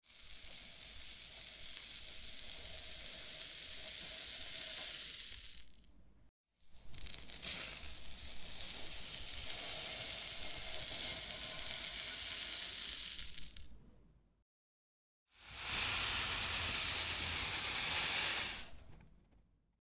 Сыпется песок три звука